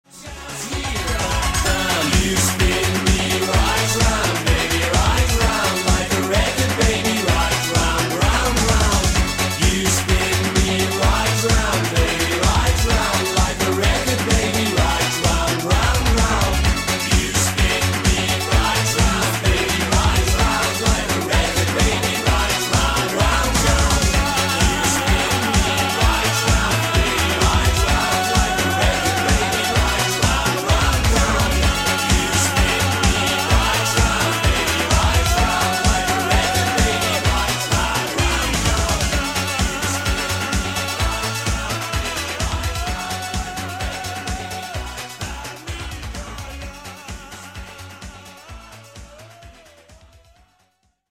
• Качество: 128, Stereo
поп
зажигательные
dance
80-е